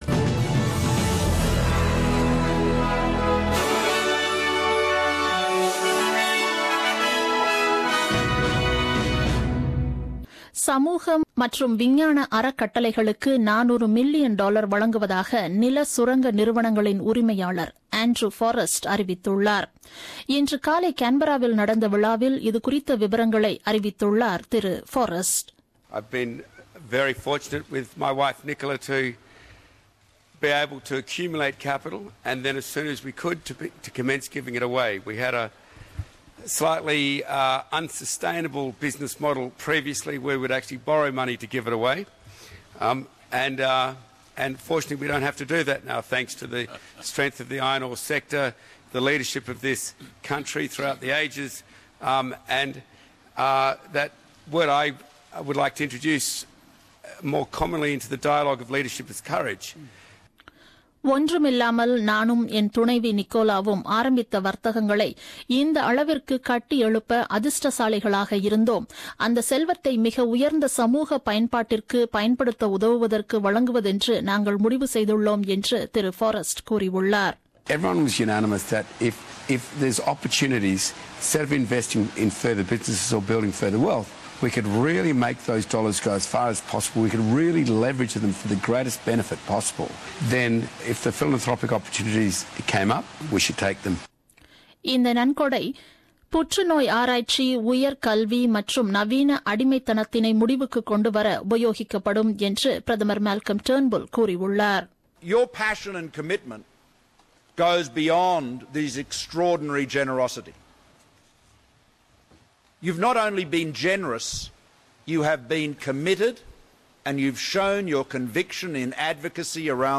The news bulletin broadcasted on 22nd May 2017 at 8pm.